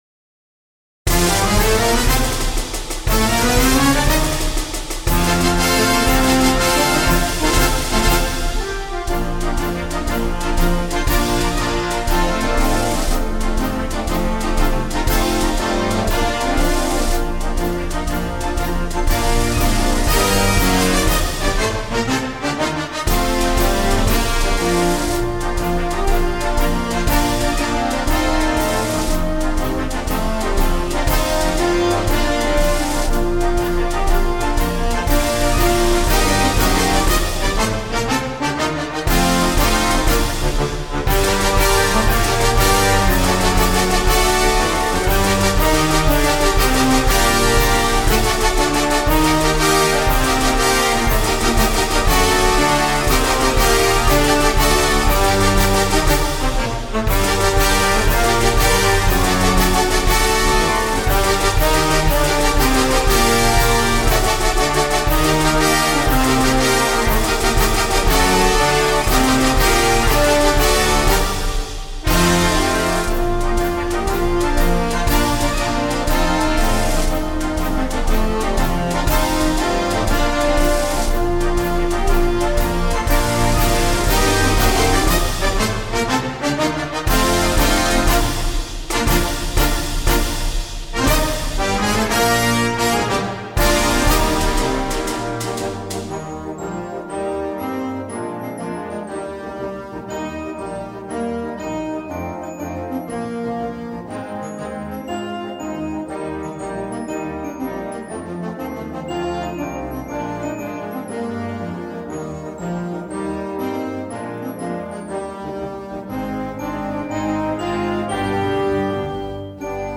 CategoryConcert/Parade March
InstrumentationPiccolo
Bb Trumpets 1-2-3
Side Drum
Glockenspiel